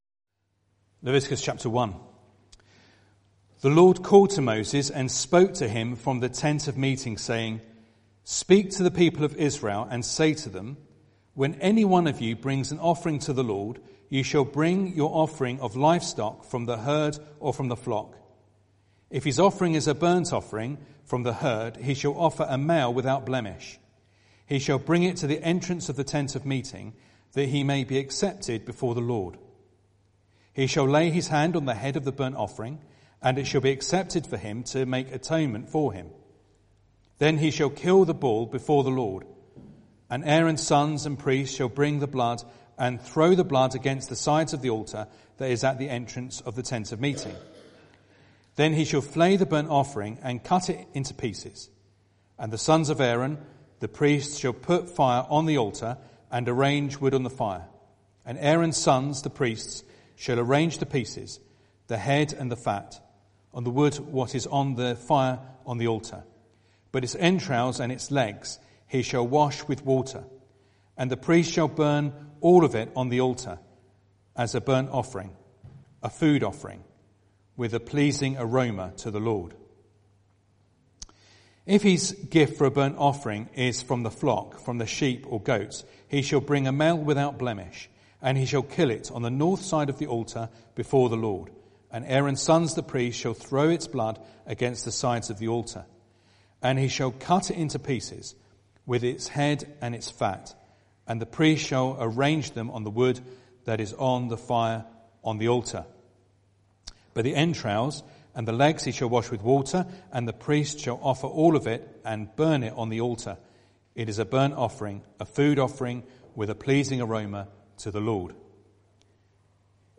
Leviticus 1 Service Type: Sunday Evening Bible Text